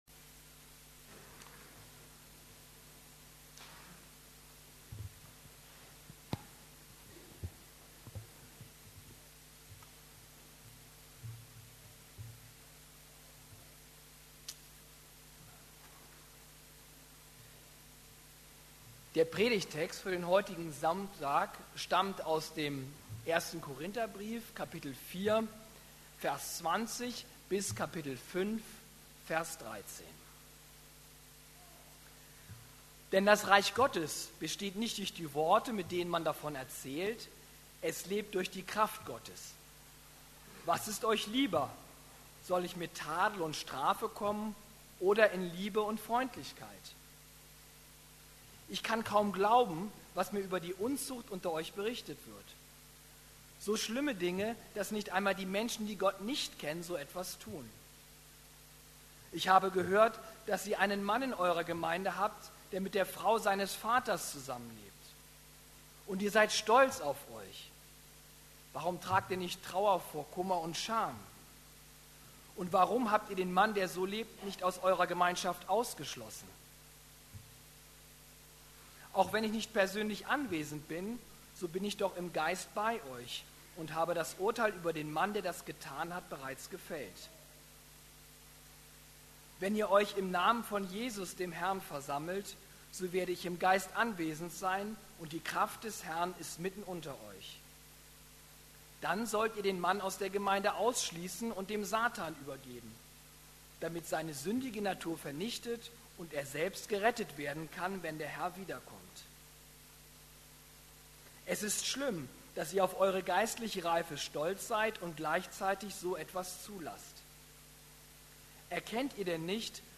Sex, Sauerteig und eine Schwiegermutter: auf der Suche nach Heiligkeit ~ Predigten der LUKAS GEMEINDE Podcast